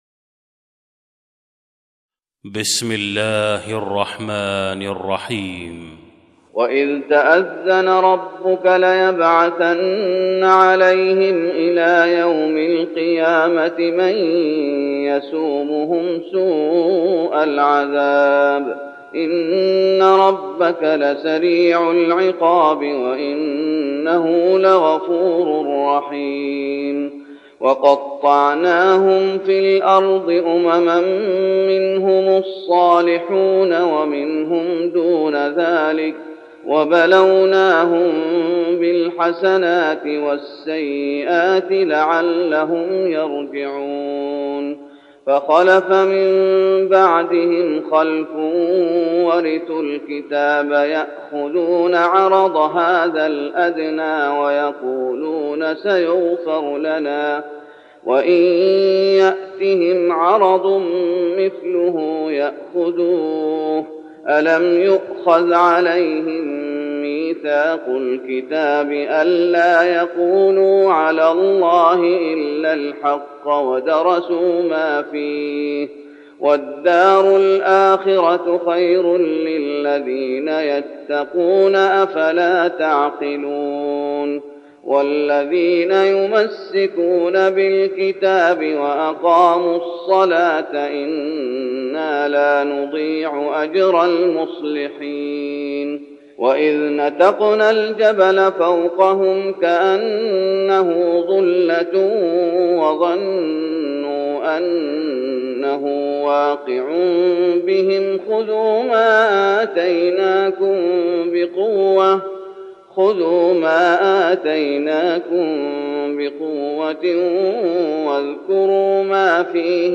تهجد رمضان 1412هـ من سورة الأعراف (167-206) Tahajjud Ramadan 1412H from Surah Al-A’raf > تراويح الشيخ محمد أيوب بالنبوي 1412 🕌 > التراويح - تلاوات الحرمين